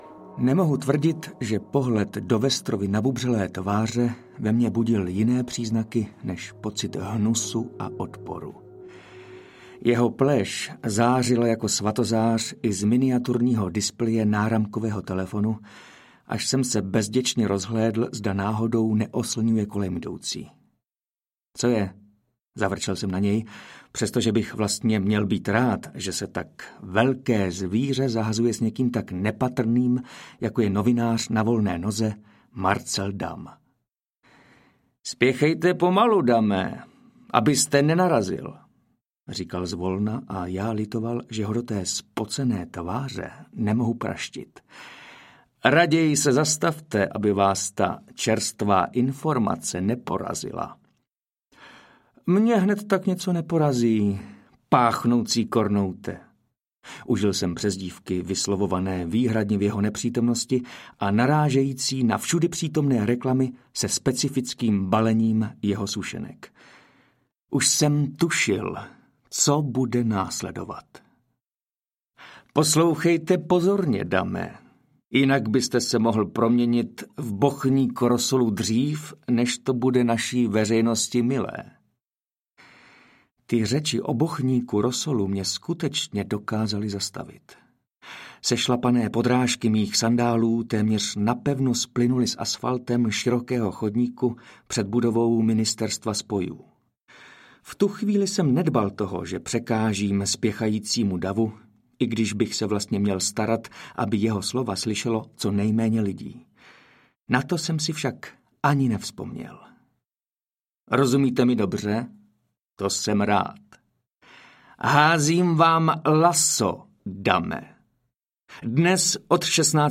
Házím ti laso, kamaráde audiokniha
Ukázka z knihy
• InterpretMartin Myšička